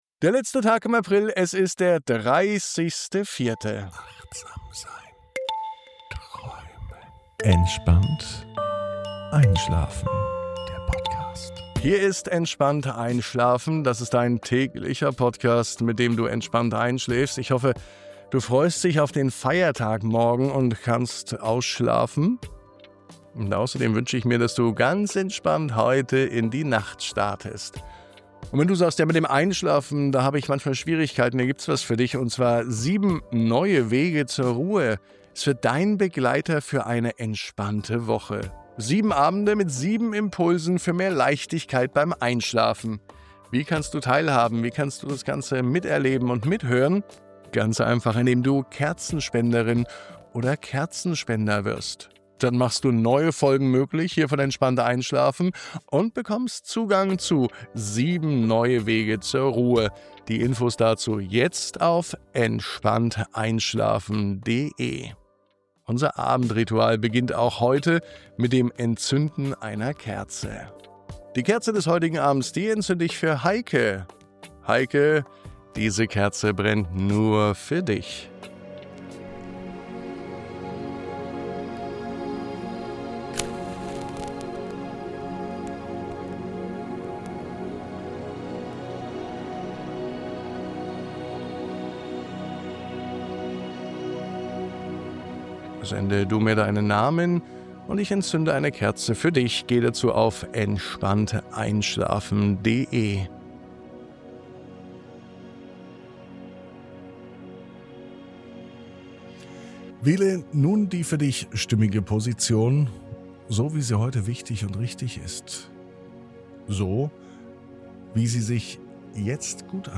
Nachdem du deinen Körper entspannt hast und deine Atmung zur Ruhe kommt, öffnest du eine Tür und findest dich mitten in dieser friedlichen Naturwelt wieder. Ein Pavillon, umrankt von Lianen und Blüten, lädt dich ein, Platz zu nehmen. Du hörst das Summen der Bienen, das Zwitschern der Vögel, das Rascheln der Blätter – Klänge, die dich sanft tragen.